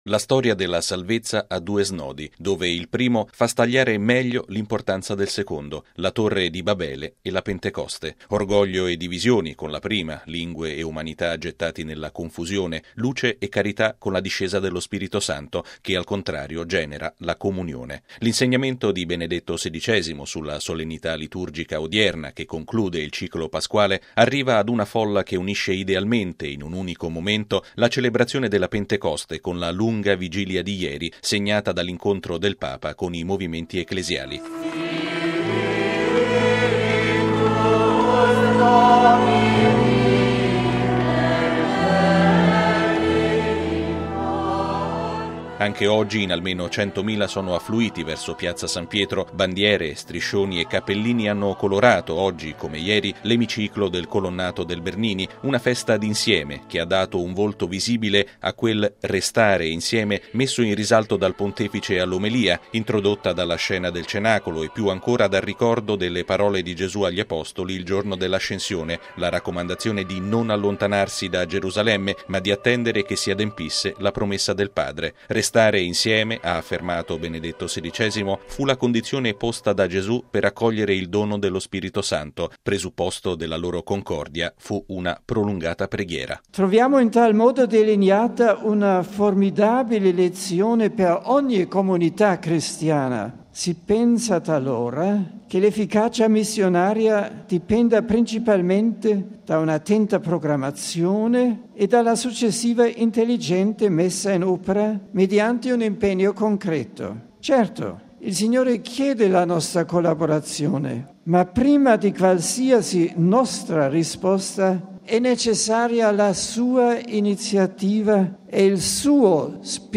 Benedetto XVI ha celebrato con questi pensieri la solennità della Pentecoste, durante la Messa presieduta questa mattina in Piazza San Pietro davanti a circa 100 mila fedeli.